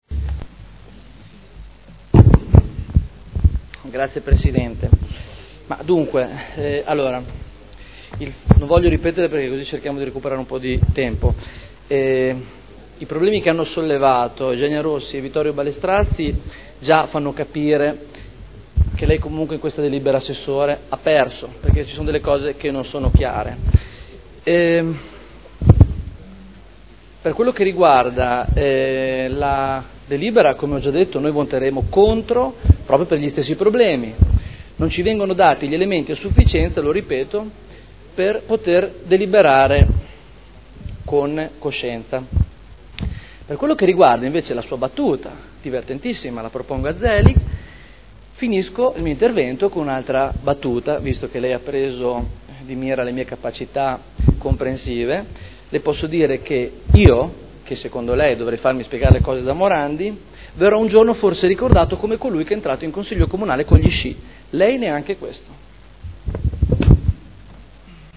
Seduta del 30/05/2011. Dichiarazione di voto su proposta di deliberazione: Proroga e integrazione del diritto di superficie assegnato a Equipenta Srl per sport equestri in Via Contrada